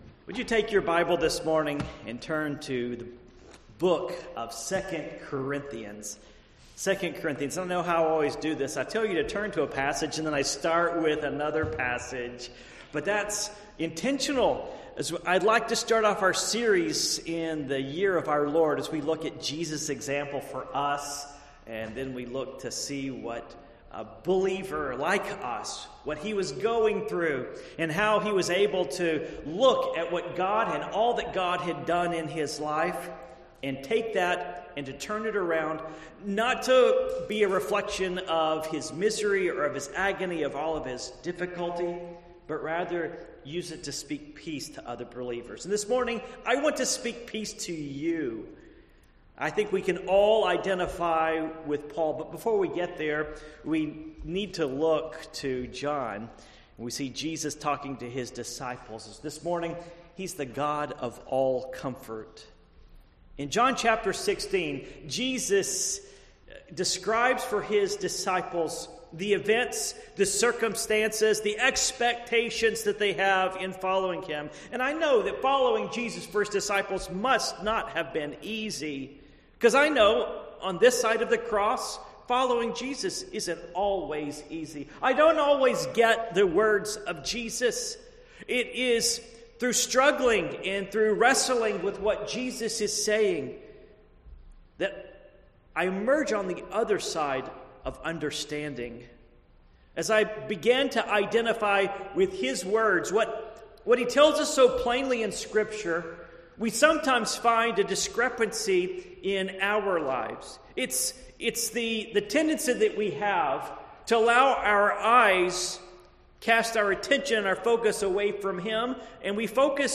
Passage: 2 Corinthians 1:1-11 Service Type: Morning Worship